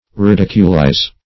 Search Result for " ridiculize" : The Collaborative International Dictionary of English v.0.48: Ridiculize \Ri*dic"u*lize\, v. t. To make ridiculous; to ridicule.
ridiculize.mp3